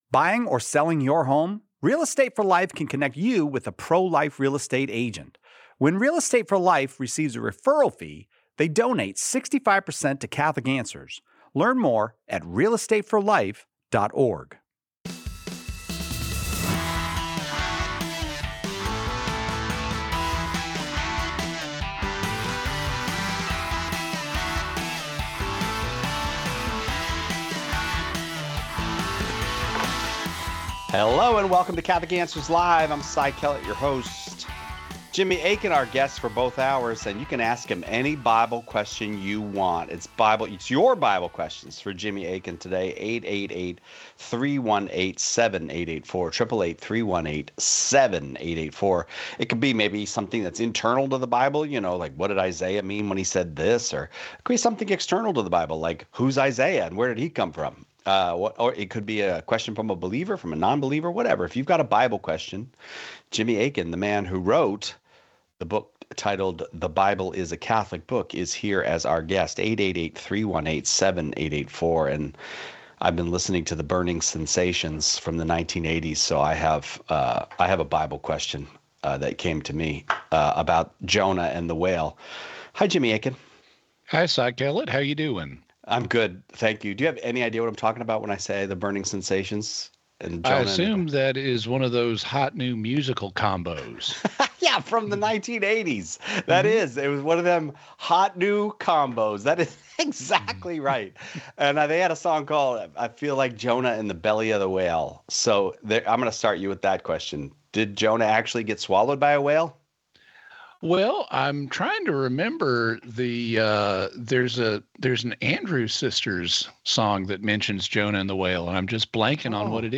In this episode of Catholic Answers Live , Catholic Answers apologists tackle this question along with several others about Scripture and theology. They discuss how to prepare for conversations with Muslims using the Bible, why giants appear after the flood, and whether Catholics can accept the Orthodox canon as inspired.
A wide-ranging discussion on biblical interpretation and Catholic teaching.